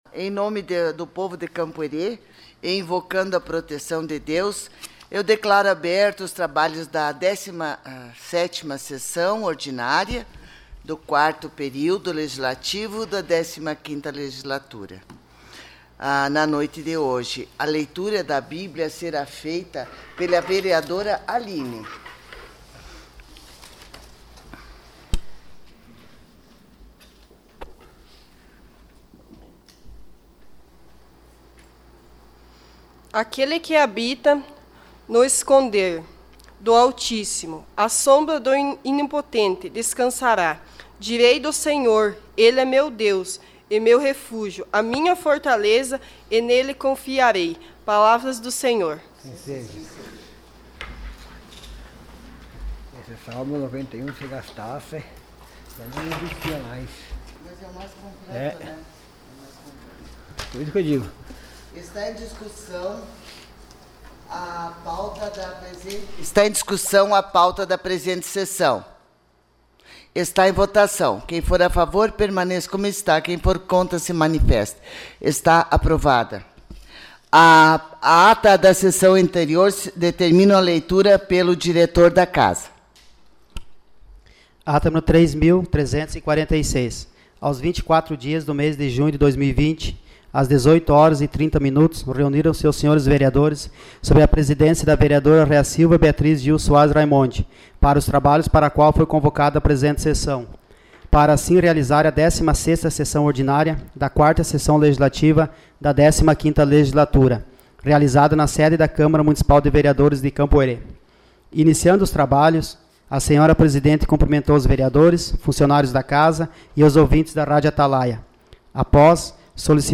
Sessão Ordinária 29 de junho de 2020